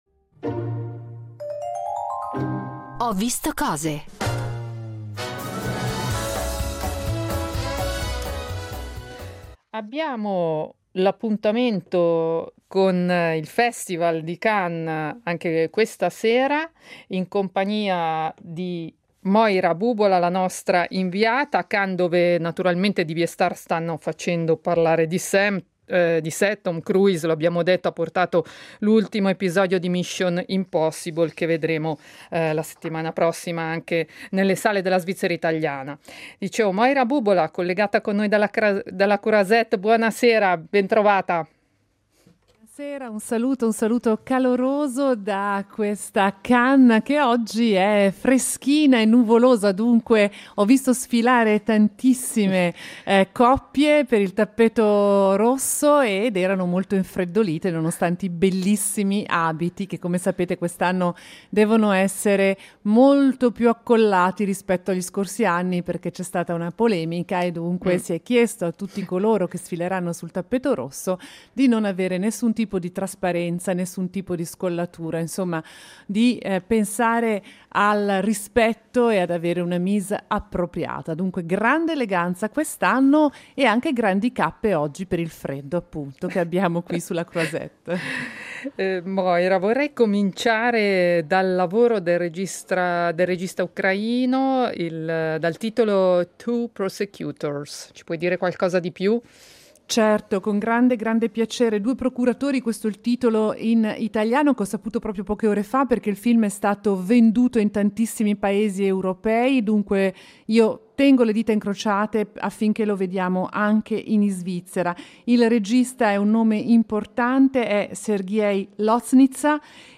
Il Festival del cinema di Cannes in diretta